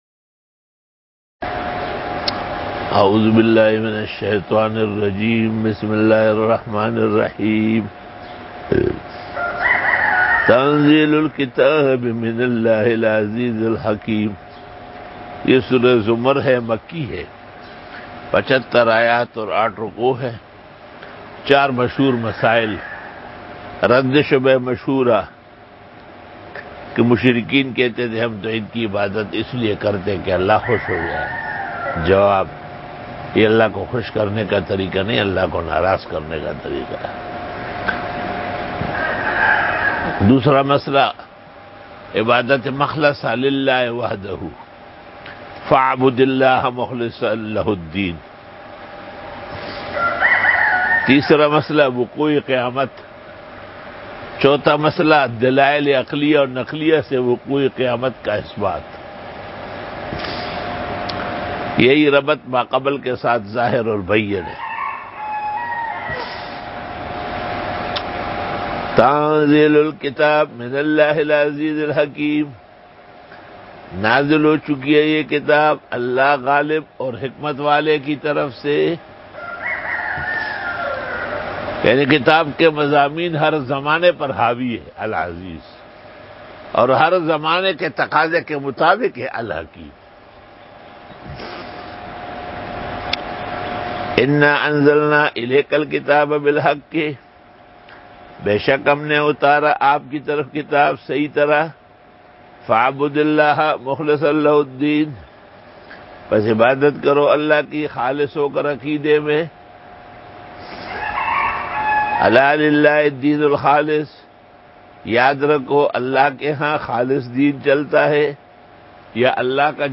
58 Quran Tafseer 10 Jun 2020 (18 Shawwal 1441 H) Wednesday Day 58
Dora-e-Tafseer 2020